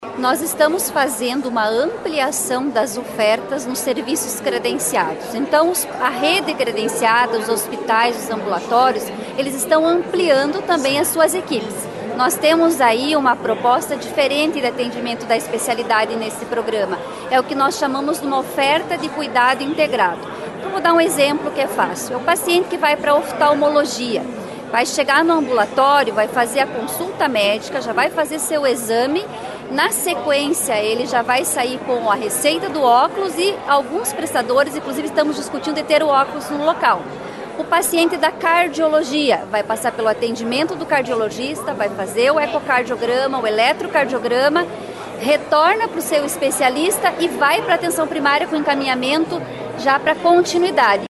A secretária municipal de saúde, Tatiane Filipak, detalhou como funciona o encaminhamento dos exames para os pacientes.